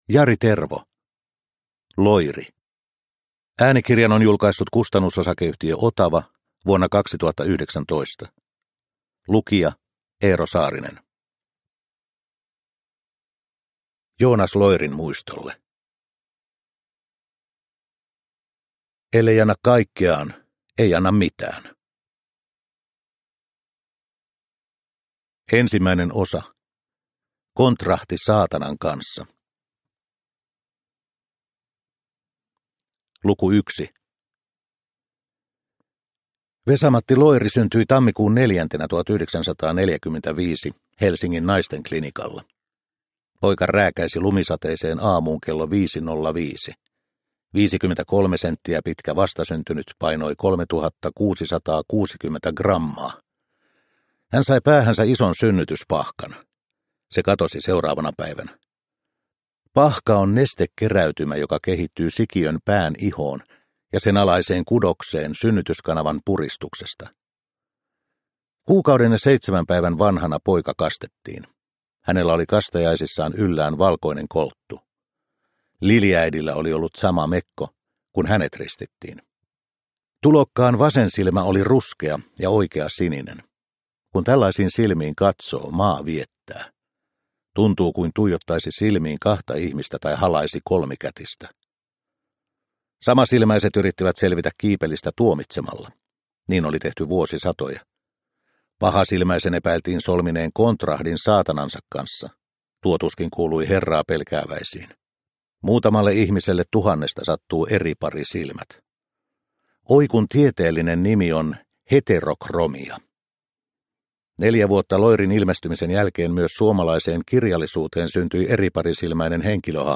LOIRI. – Ljudbok – Laddas ner